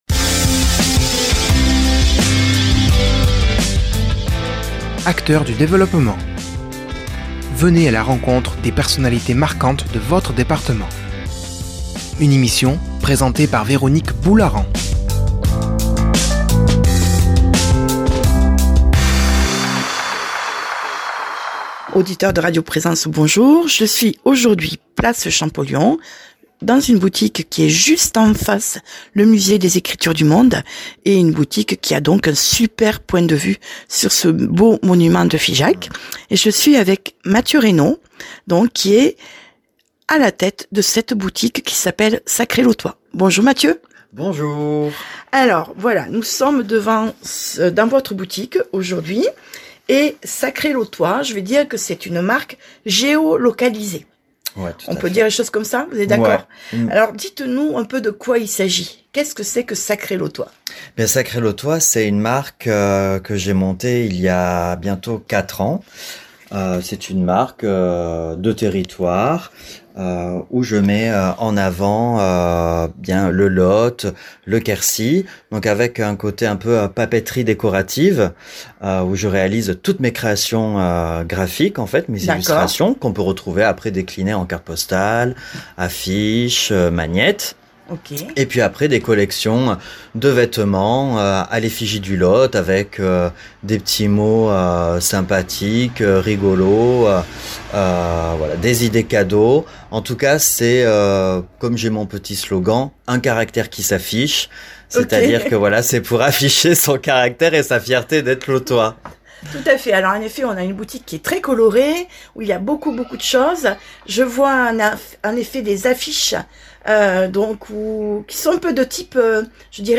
déplacé le micro de Radio Présence à quelques pas des studios
Place Champollion à Figeac